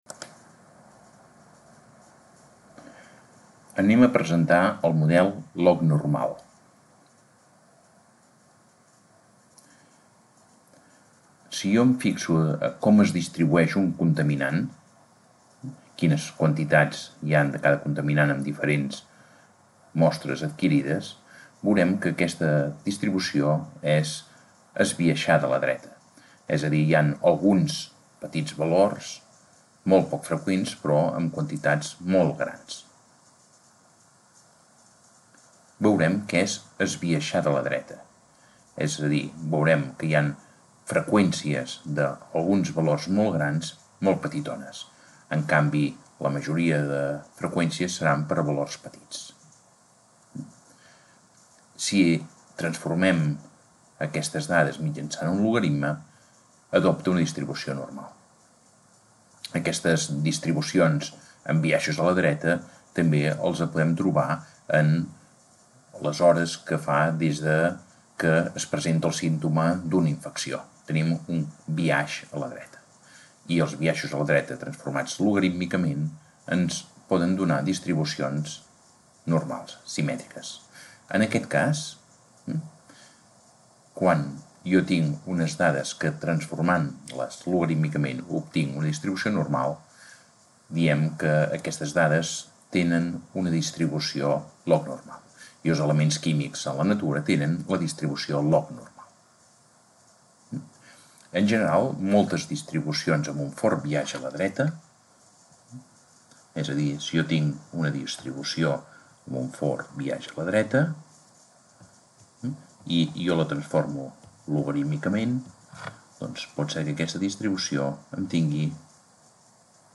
Lesson of the subject Theory of Statistics on the log-normal model